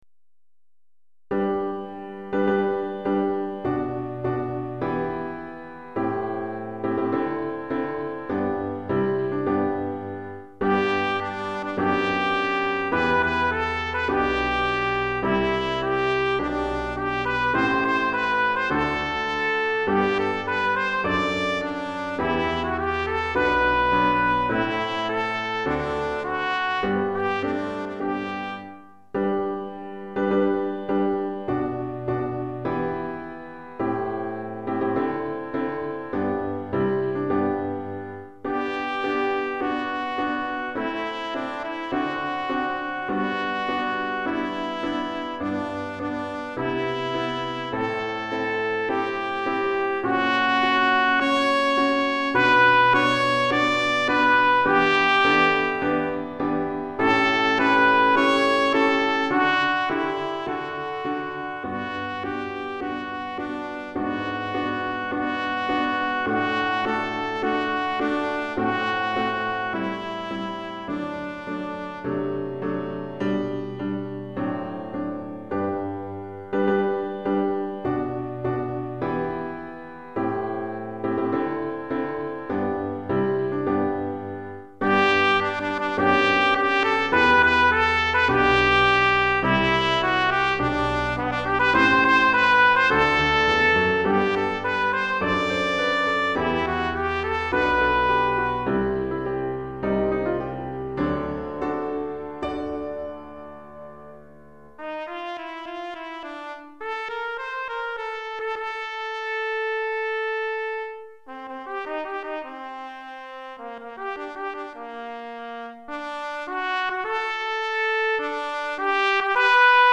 Pour trompette (ou cornet) et piano
Trompette et piano